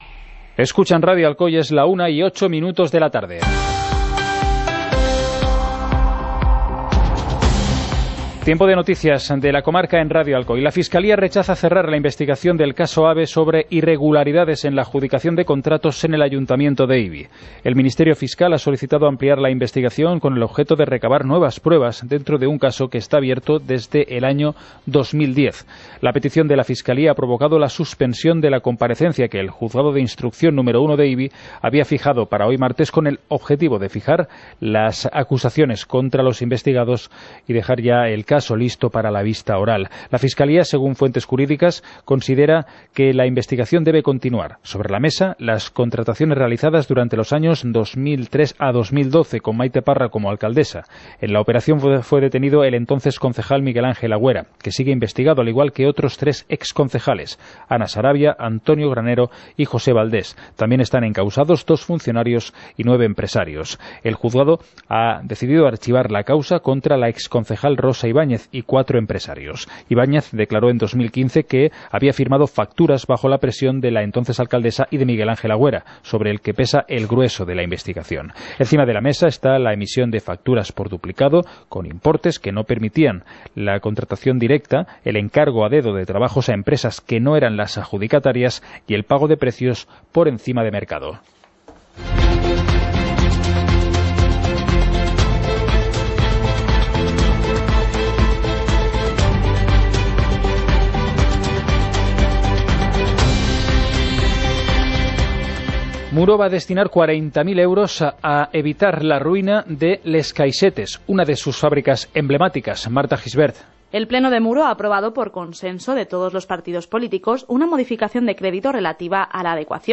Informativo comarcal - martes, 02 de octubre de 2018